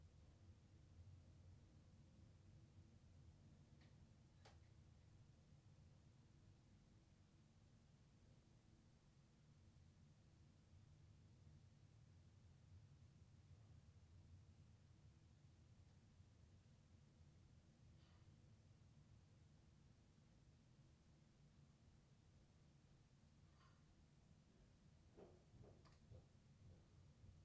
Room Tone Sample
This is a recording of the ambient noise or silence in the acoustically-treated space.